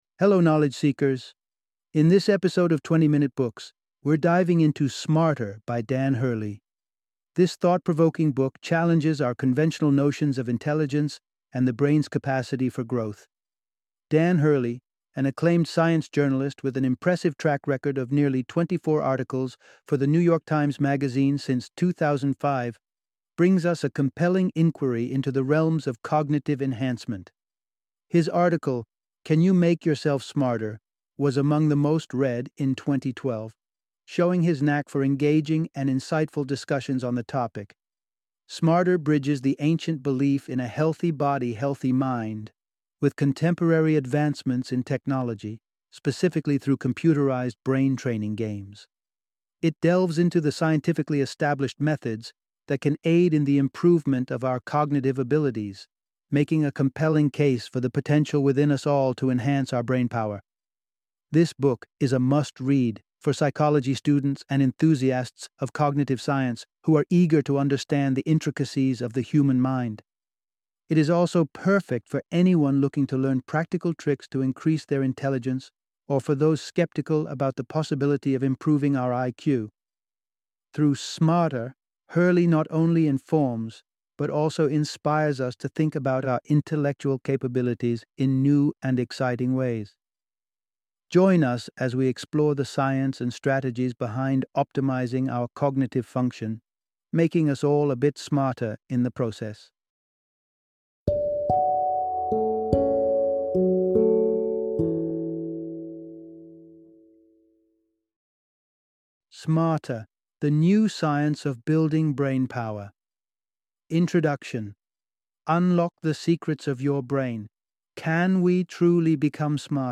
Smarter - Book Summary